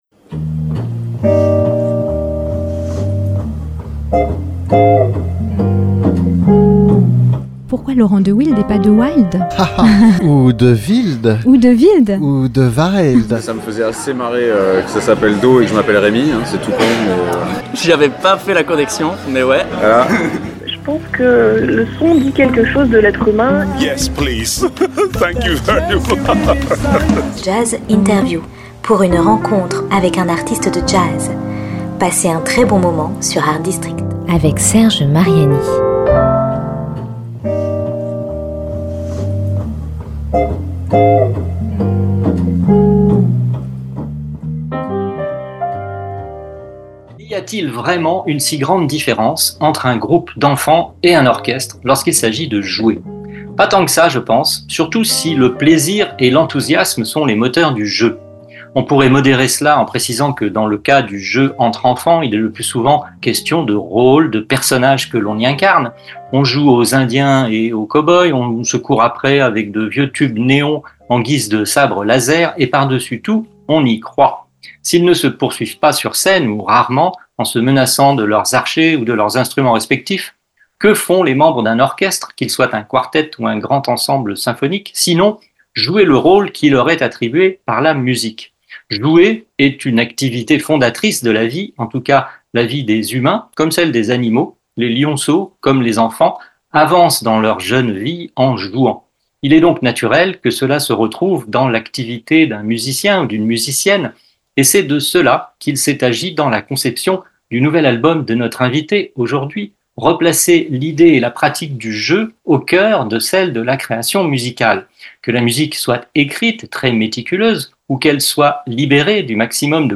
JAZZ INTERVIEW mardi et vendredi à 14h.